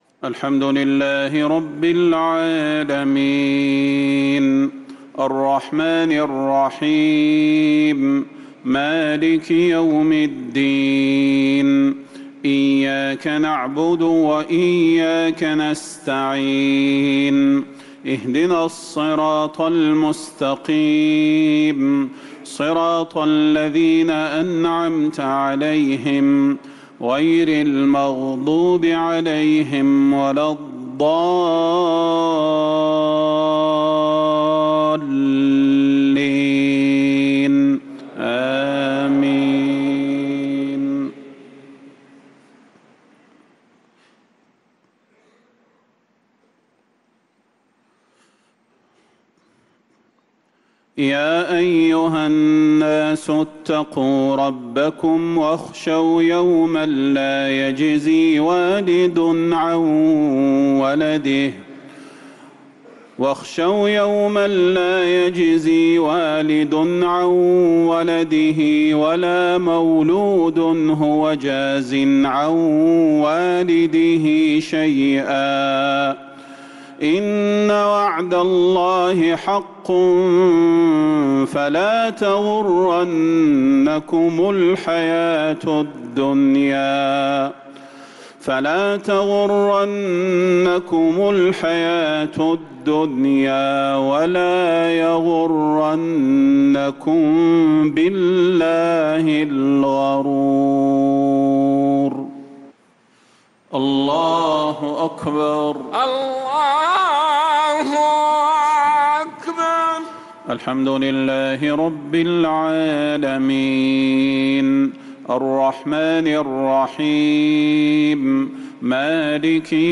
صلاة المغرب للقارئ صلاح البدير 19 صفر 1446 هـ
تِلَاوَات الْحَرَمَيْن .